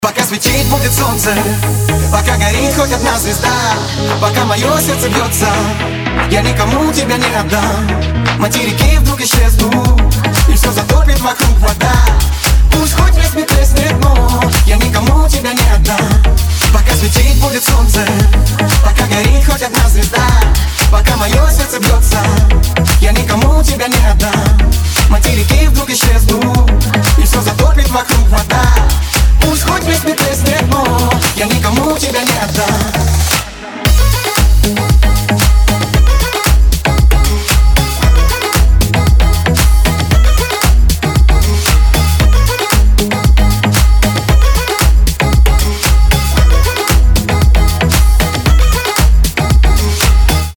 • Качество: 256, Stereo
dance
Club House
house
vocal